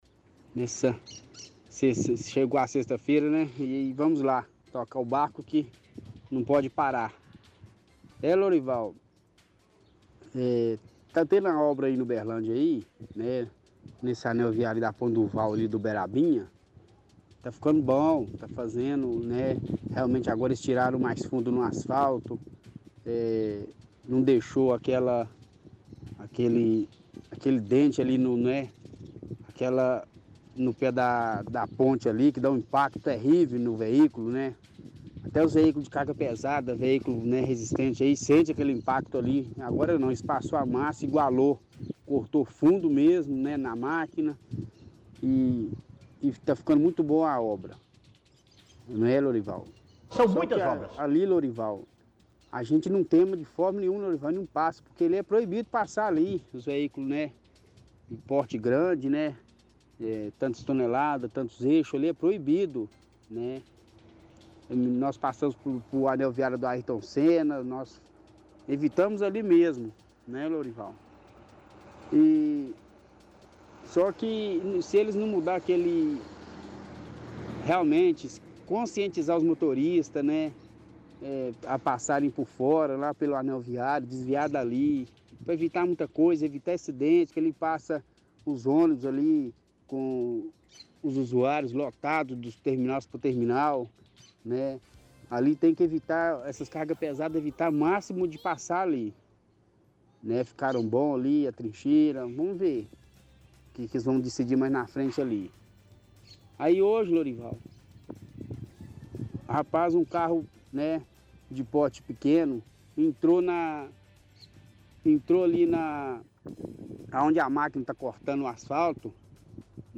– Ouvinte elogia obra sendo feita na Ponte do Vau.